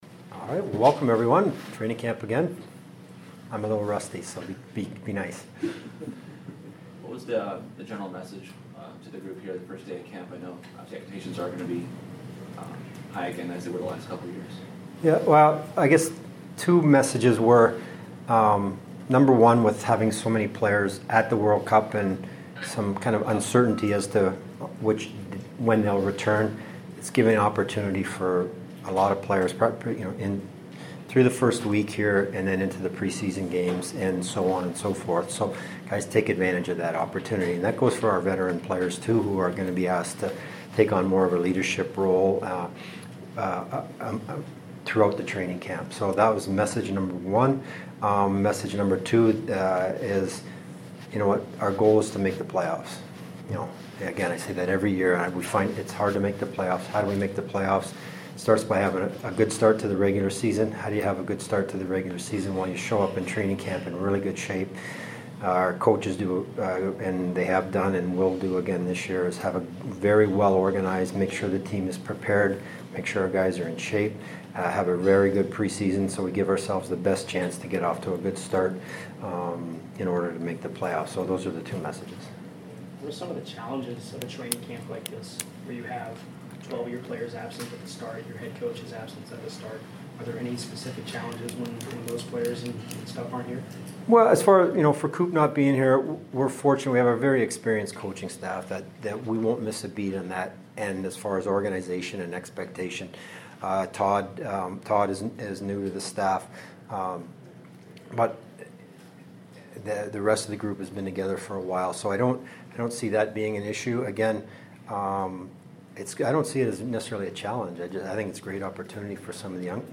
Lightning general manager Steve Yzerman addresses the media on the first day of training camp.